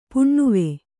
♪ puṇṇuve